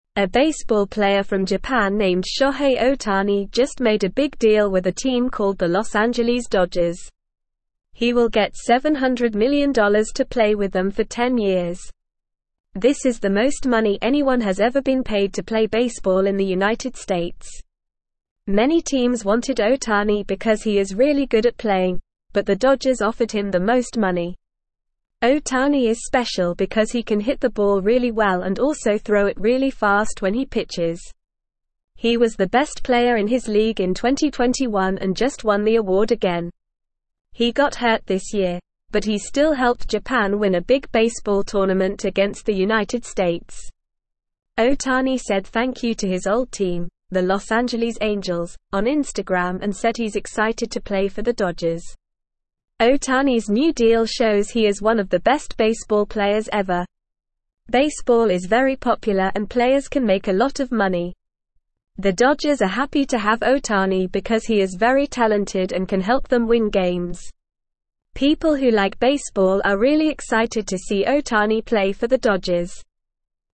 Normal
English-Newsroom-Lower-Intermediate-NORMAL-Reading-Japanese-Baseball-Star-Signs-Big-Deal-with-Los-Angeles.mp3